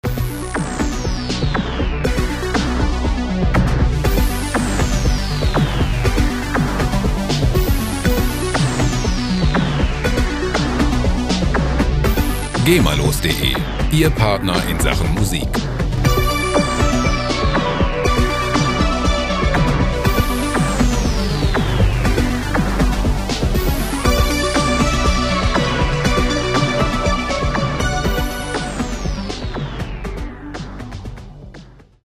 Electro Loops
Musikstil: Synthwave
Tempo: 120 bpm